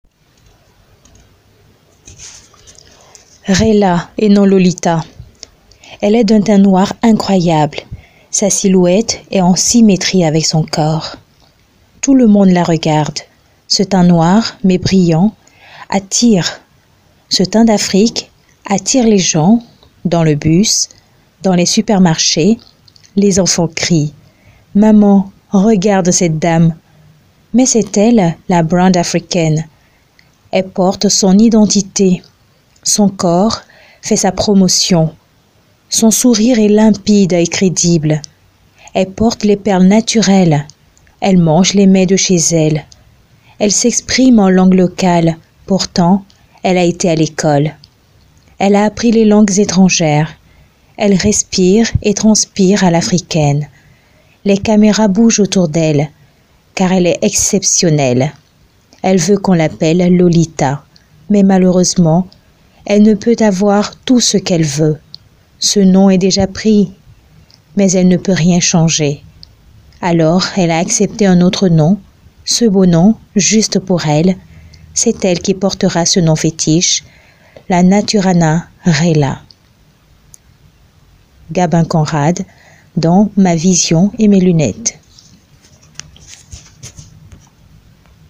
Poème: Extrait du livre "Ma Vision et mes Lunetes" de Gabin Conrad